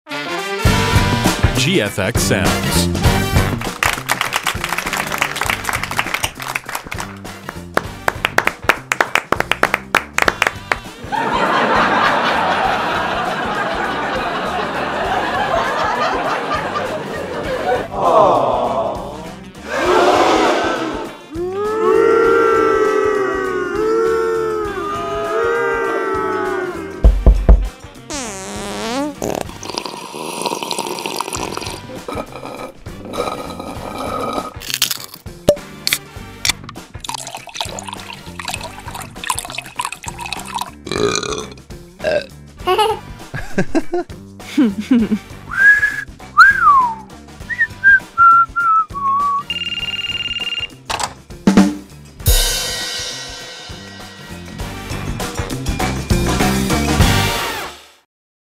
This bundle features a wide array of funny and popular sounds designed for sitcoms and TV comedies. It includes different categories: audience reactions, character emotions, sitcom jingles (from intros and outros to live ads and transitions), and punchlines that capture the perfect comedic timing. Also included are miscellaneous sounds such as doors opening and closing, everyday household noises, and the familiar sounds of eating and drinking, often heard in this genre.
Type: Sound Design, Sitcom, Comedy